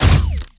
Added more sound effects.
GUNAuto_Plasmid Machinegun C Single_05_SFRMS_SCIWPNS.wav